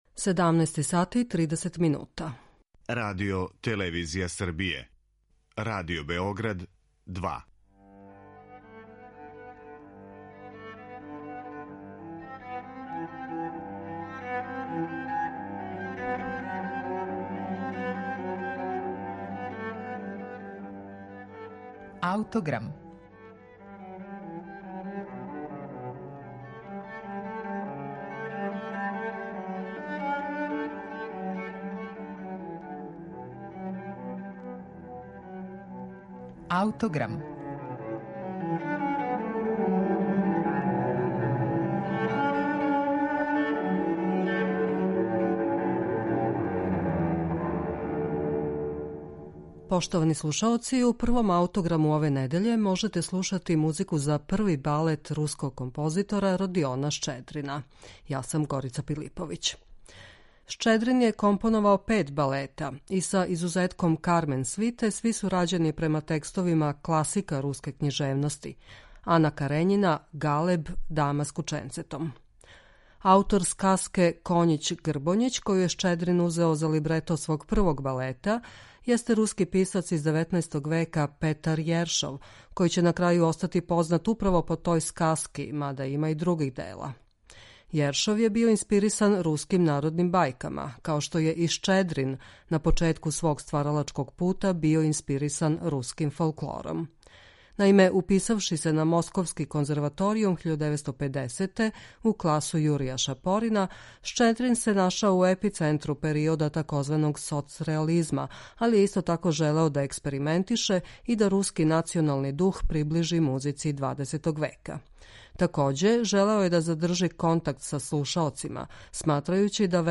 музику за први од укупно пет балета